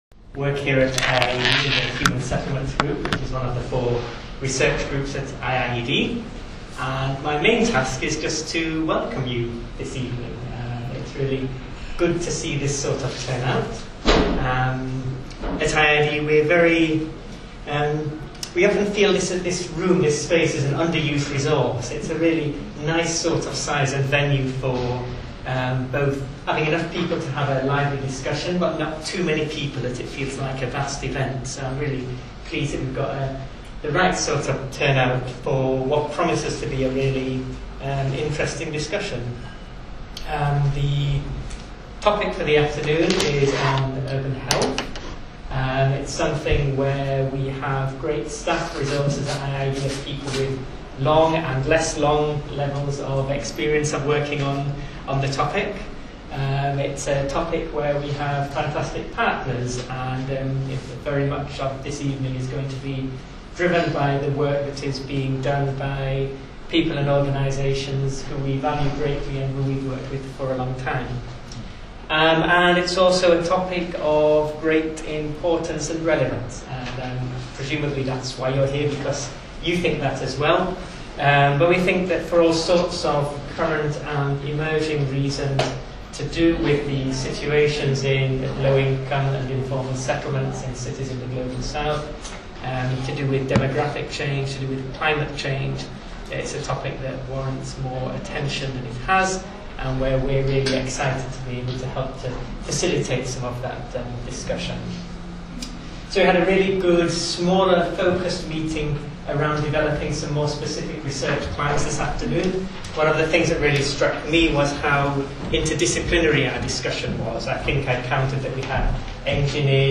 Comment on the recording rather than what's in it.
IIED-HSG-health-meeting.mp3